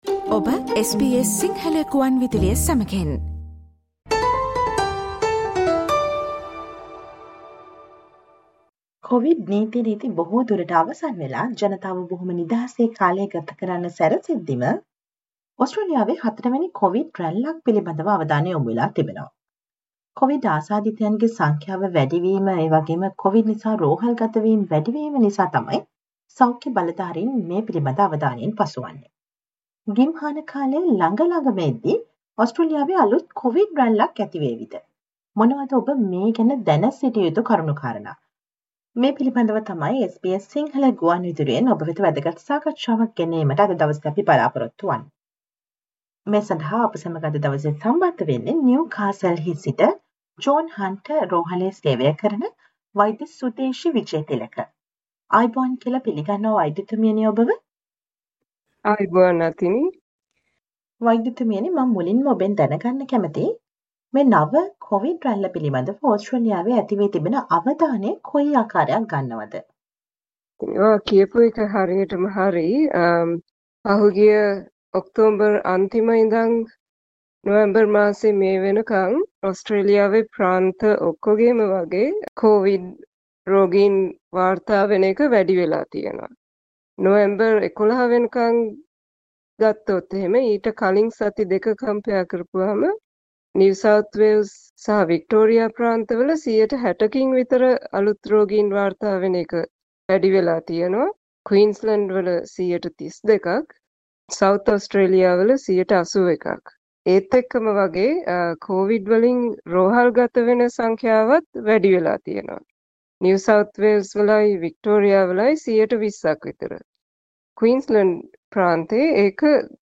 Listen to the SBS Sinhala radio interview on the matter of concerns over the new covid wave in Australia